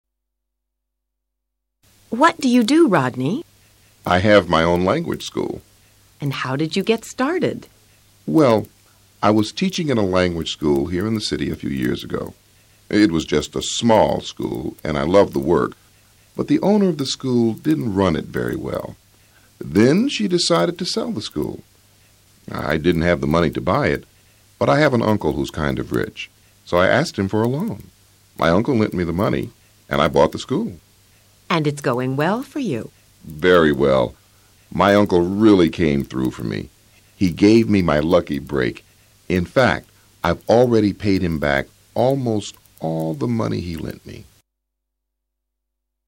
Tres personas exponen cómo lograron alcanzar sus objetivos profesionales.